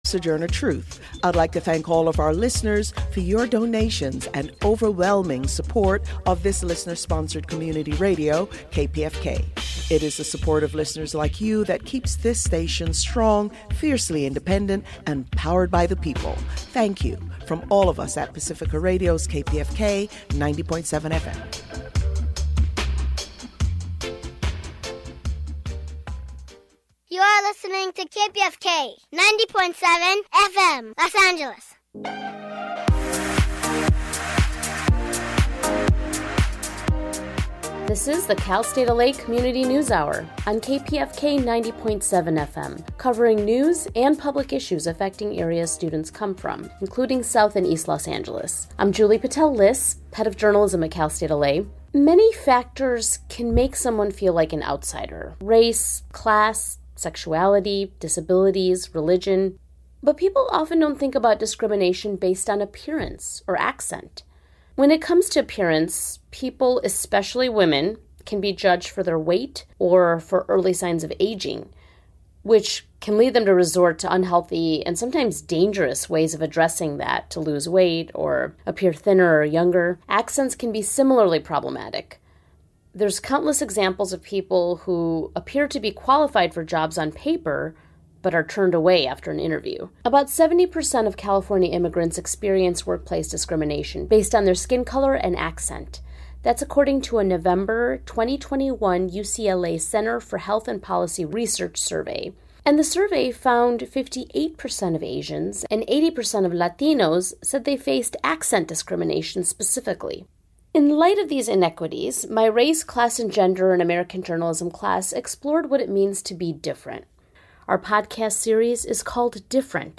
Beneath the Surface features interviews with leading thinkers and activists on the important issues of the day, with a focus on deeper analysis.